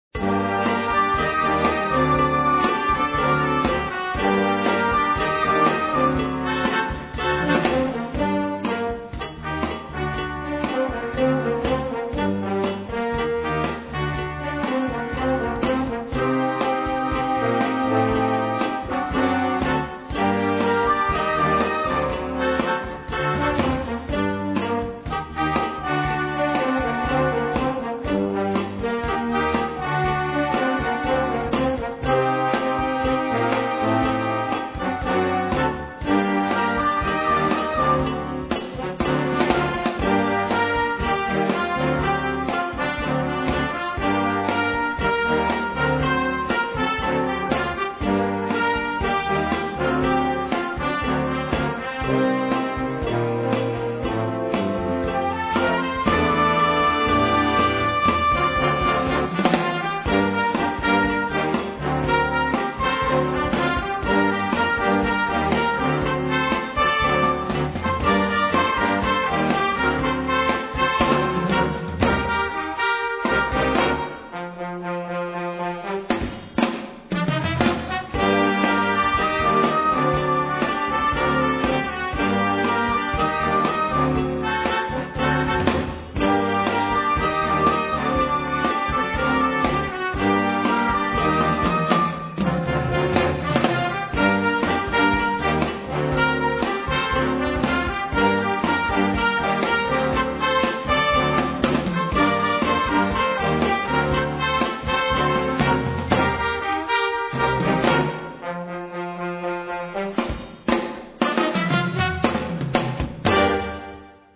Gattung: Pop-Song
Besetzung: Blasorchester